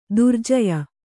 ♪ durjaya